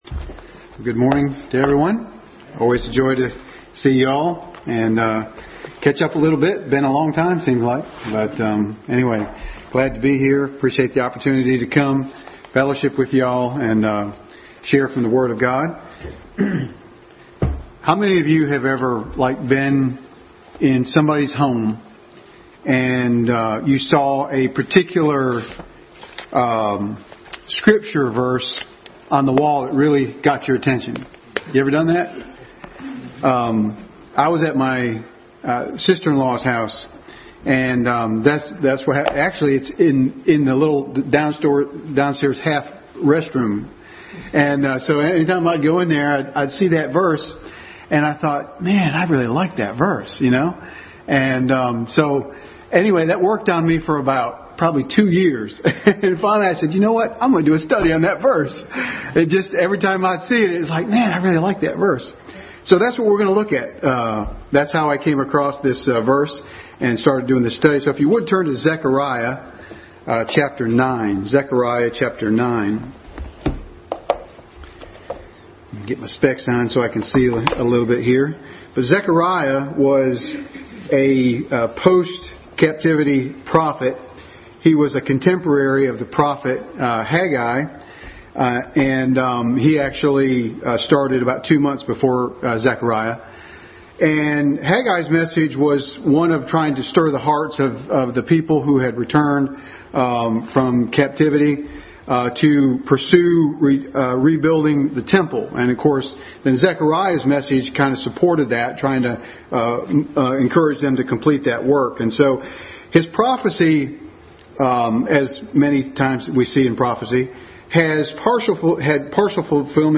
Adult Sunday School Class message.